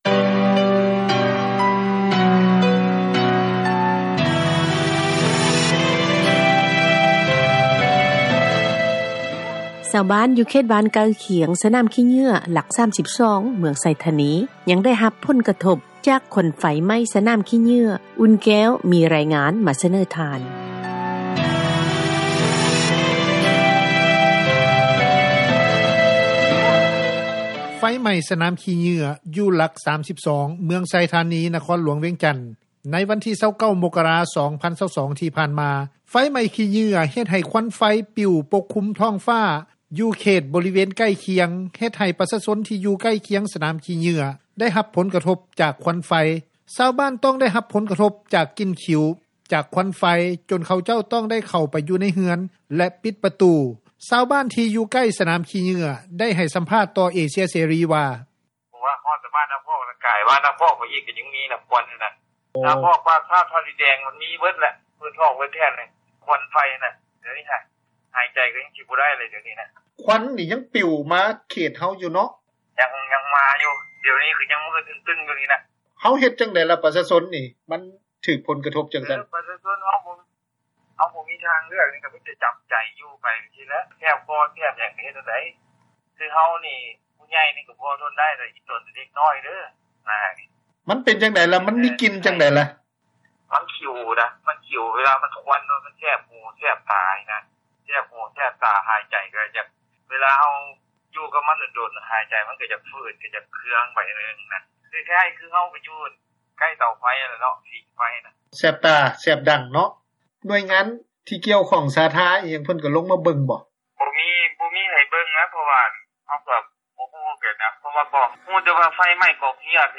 ຊາວບ້ານທີ່ຢູ່ໃກ້ນາມຂີ້ເຫຍື້ອໄດ້ໃຫ້ສັມພາດ ຕໍ່ເອເຊັຽເສຣີ ວ່າ
ຊາວບ້ານອີກຄົນນຶ່ງ ໄດ້ໃຫ້ສັມພາດວ່າ.